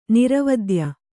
♪ niravadya